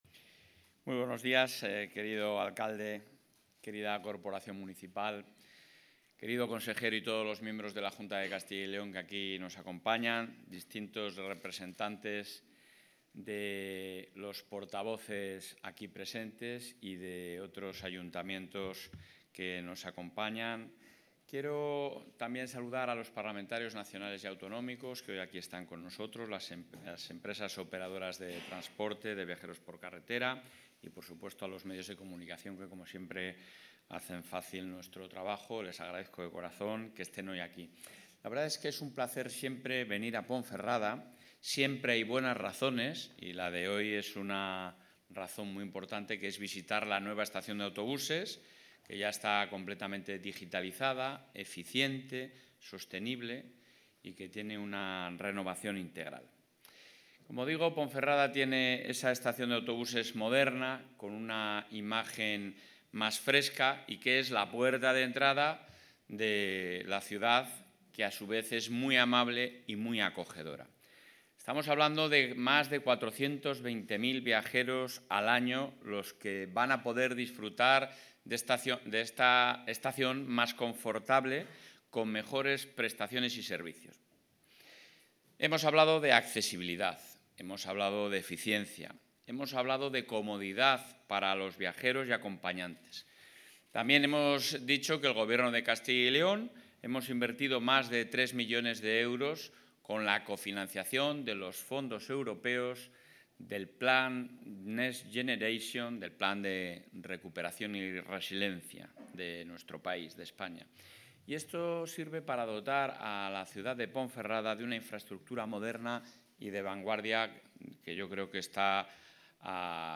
Intervención del presidente de la Junta.
El presidente de la Junta de Castilla y León, Alfonso Fernández Mañueco, ha visitado esta mañana la estación de autobuses de Ponferrada, tras la renovación integral llevada a cabo por el Ejecutivo autonómico, que ha supuesto una inversión de más de 3 millones de euros, cofinanciados con Fondos Europeos.